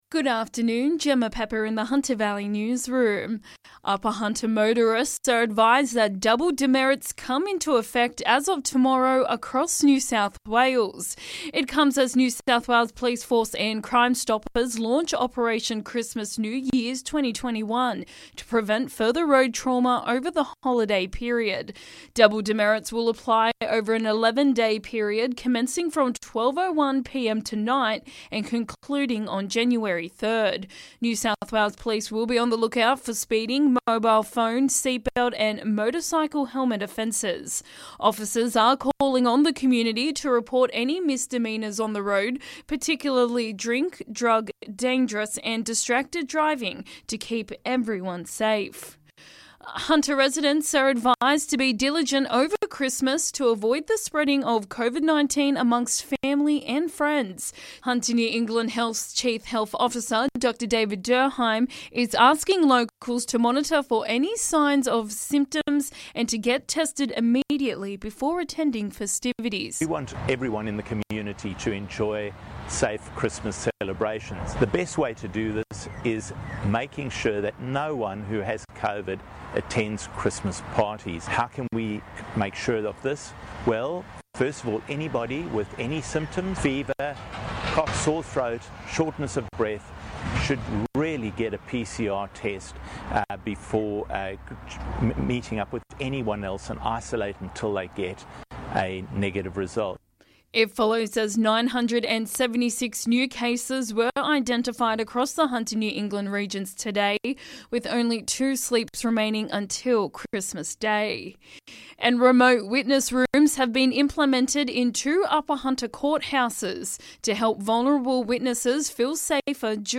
LISTEN: Hunter Valley Local News Headlines 23/12/21